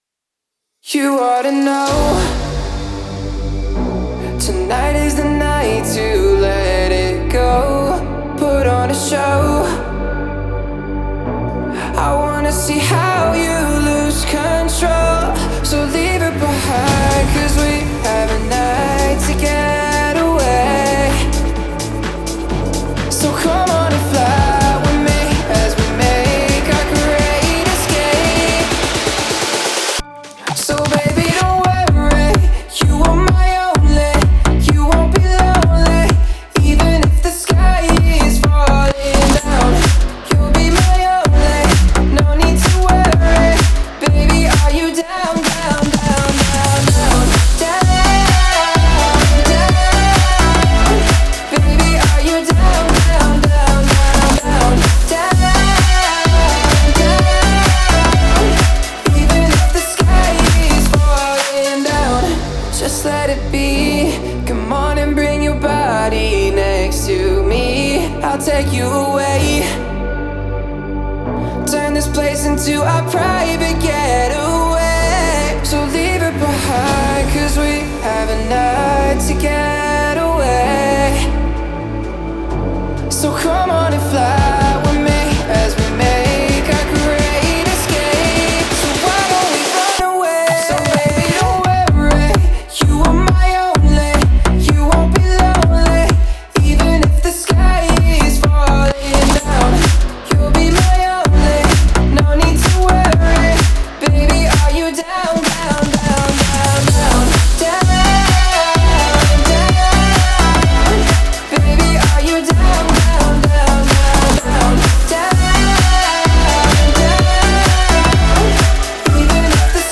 это динамичная композиция в жанре EDM